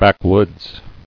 [back·woods]